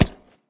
icegrass.ogg